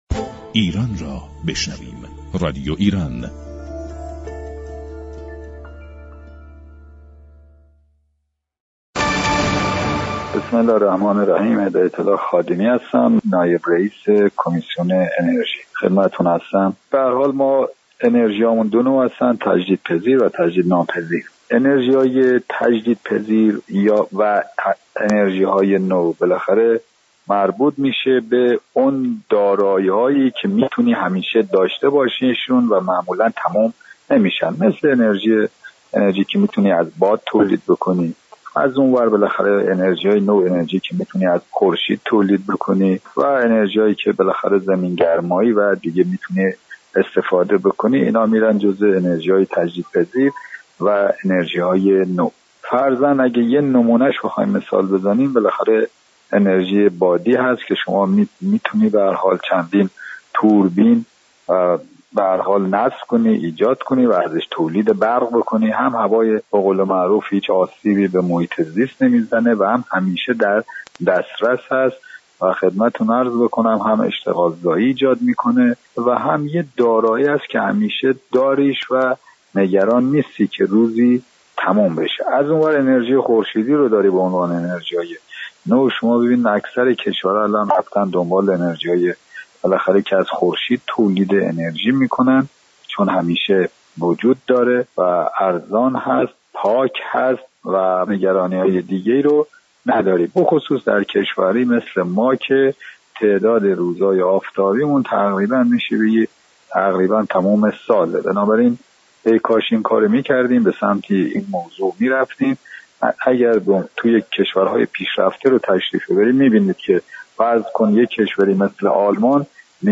نائب رییس كمسیون انرژی مجلس در گفت و گو با رادیو ایران گفت: انرژی خورشید به دلیل آنكه ارزان و پاك می باشد بیشتر كشورهای دنیا امروزه به دنبال استفاده بهینه از آن هستند.